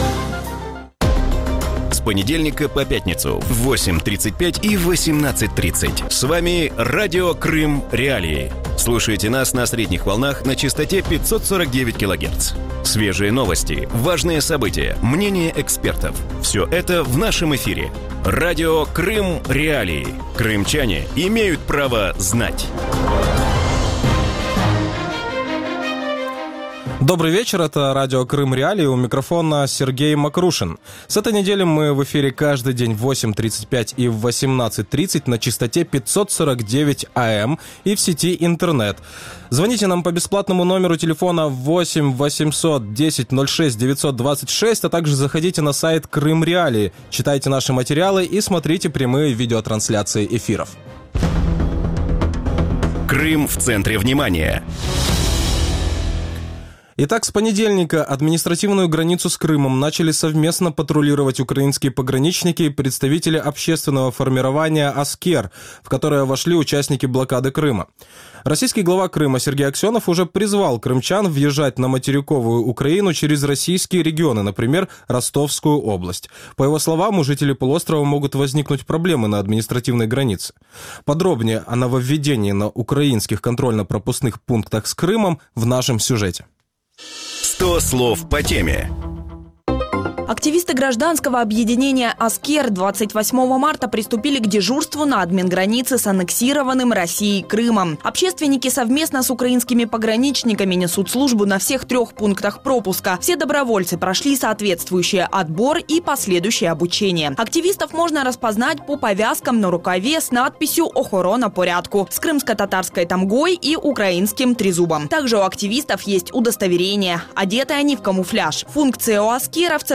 В вечернем эфире Радио Крым.Реалии обсуждают начало совместного патрулирования админграницы с Крымом Госпогранслужбой и общественным формированием «Аскер».